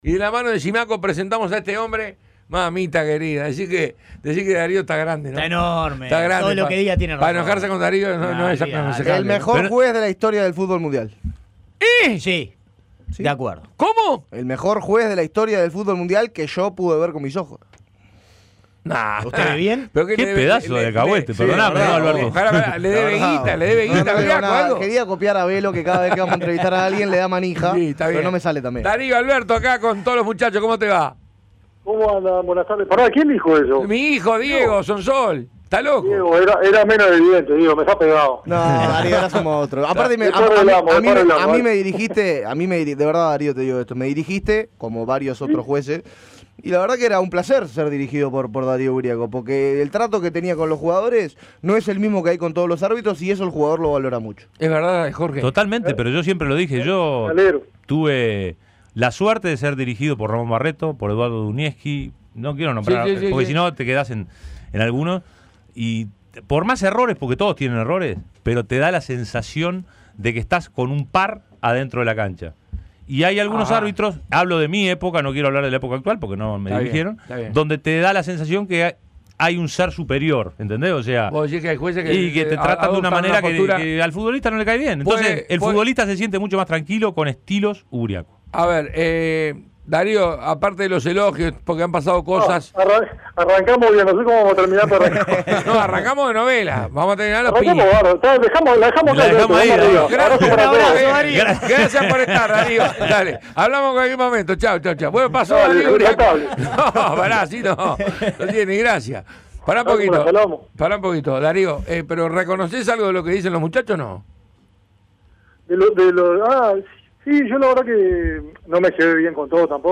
También habló sobre lo sucedido en la vuelta de la semifinal entre Lanús y River, cuando con el VAR disponible no se revisó una jugada que parecía penal. Entrevista completa.